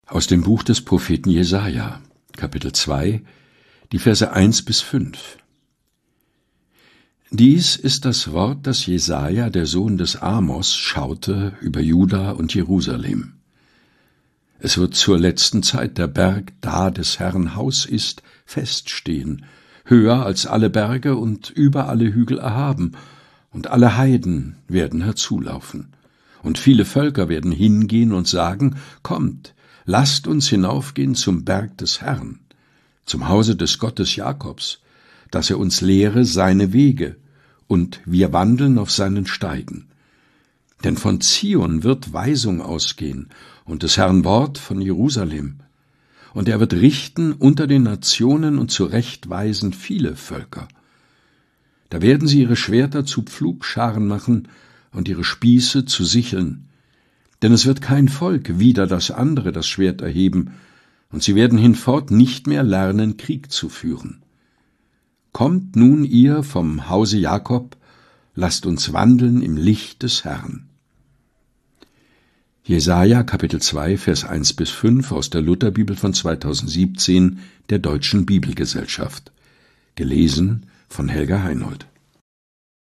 Predigttext zum 8. Sonntag nach Trinitatis 2025.